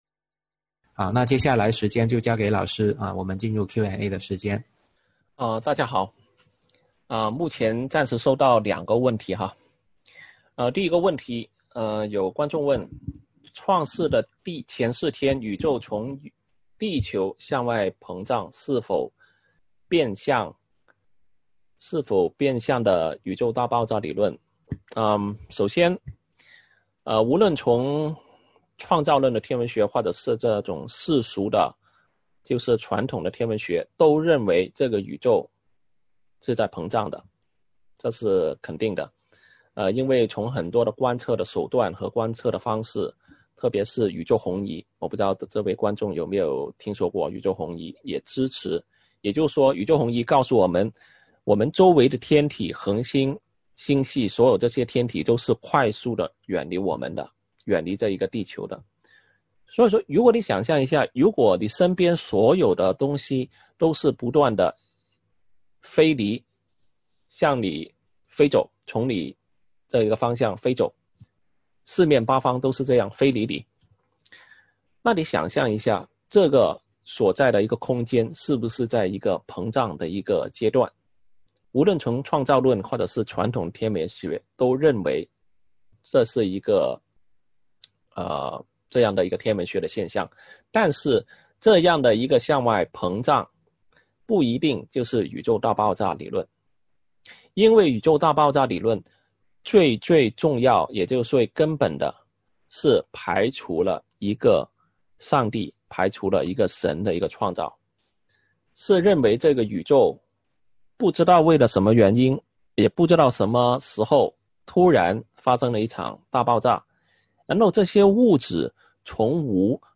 《为何我们能看见几百万光年之外的星光》讲座直播回放